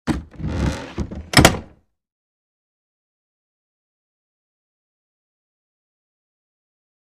Heavy Wood Door Thud Impact With Creaks And Close